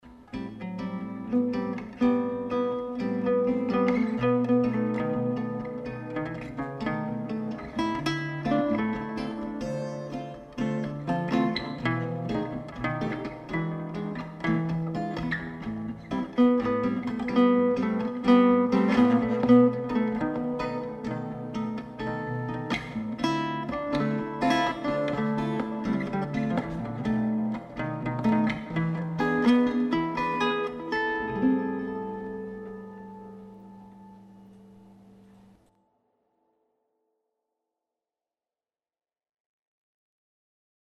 Kategoria: gitara jazzowa, jazz   |  komentarze (2)